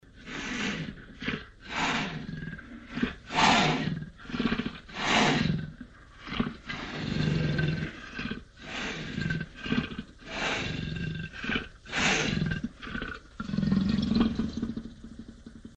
Леопард фыркает